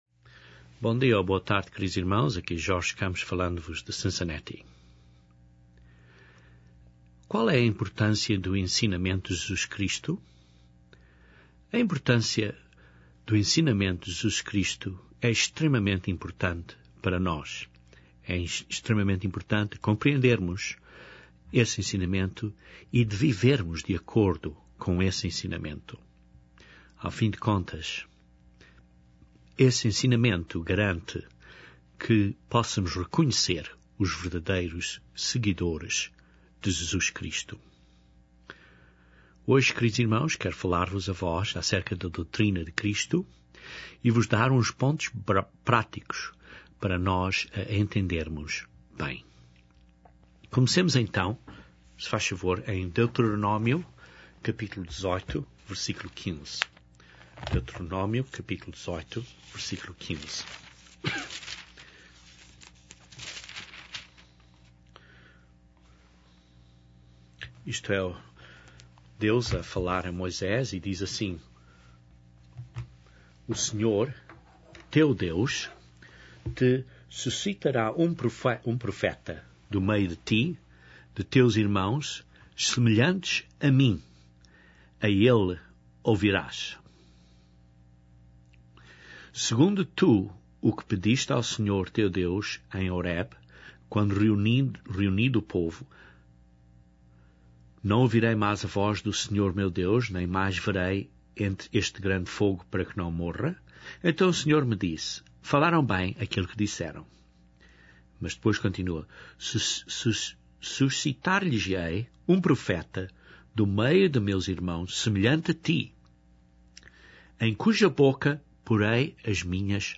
Este sermão prova que Jesus Cristo é Esse Profeta e demonstra alguns pontos práticos de como devemos seguir os ensinamentos de Cristo.